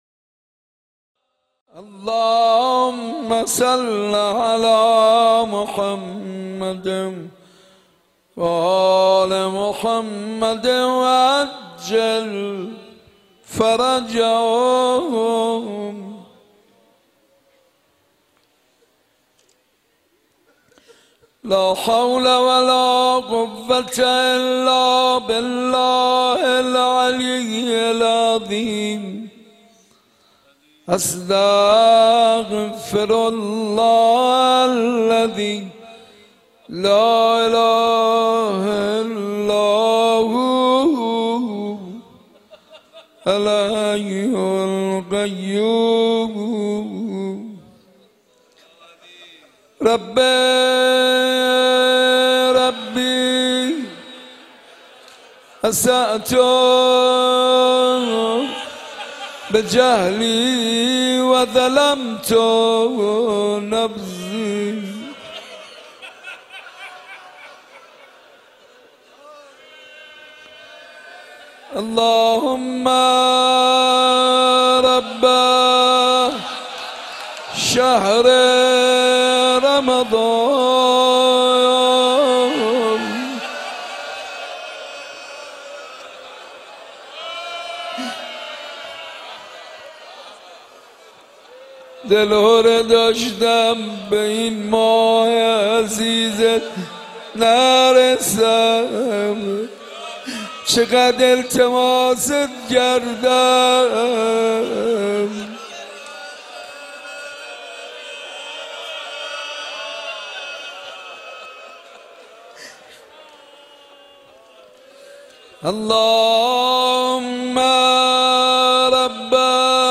مناجات با خداوند و روضه خوانی
گلچین مناجات شب اول ماه رمضان-حاج منصور ارضی-سال 1394.mp3